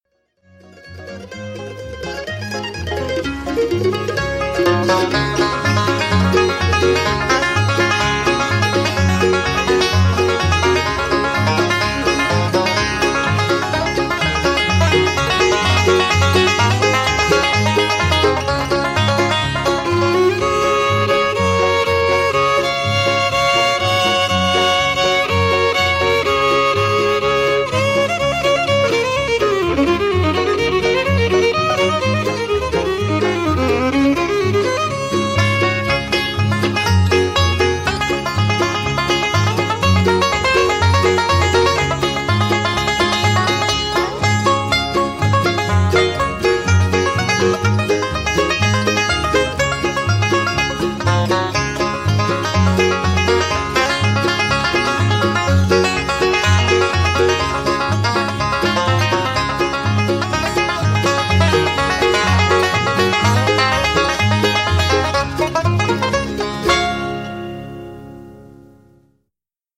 Listen to Don Reno perform "Seeing Nellie Home" on the 5-string banjo (mp3)